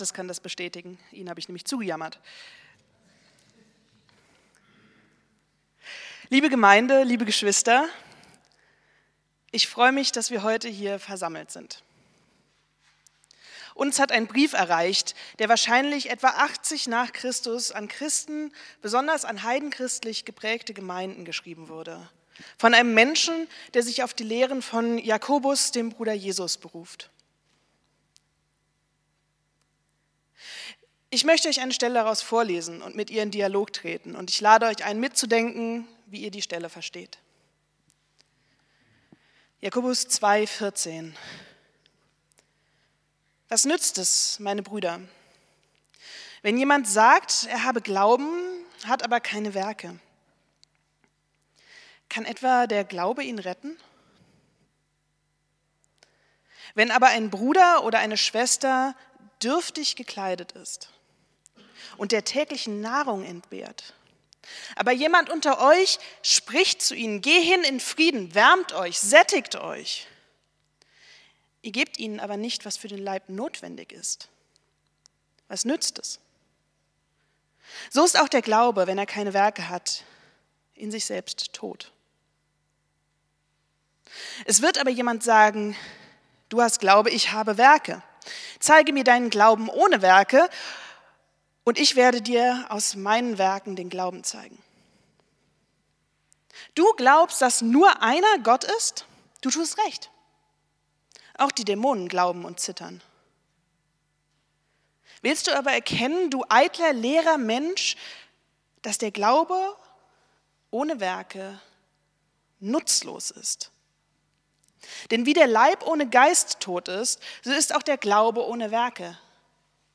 Predigt vom 19.10.2025